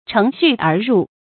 chéng xū ér rù
乘虚而入发音
成语正音 而，不能读作“ěr”。